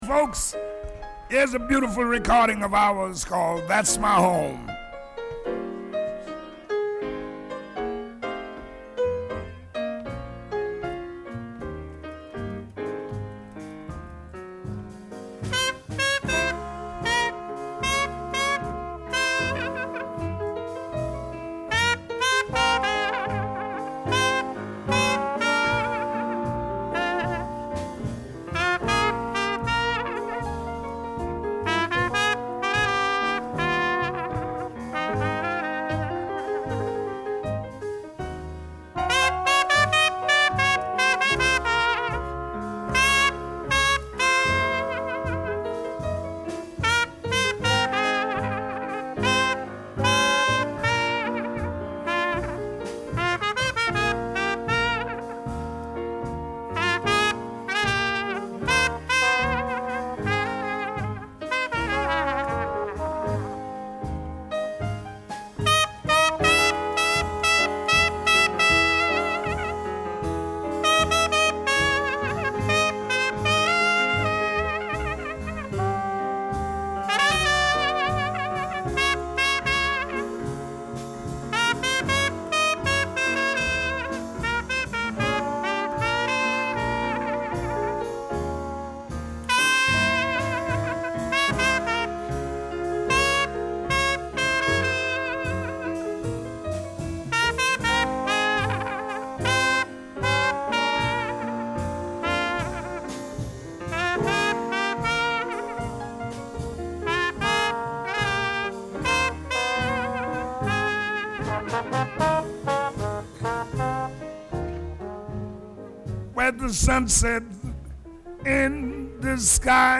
this is the finest live version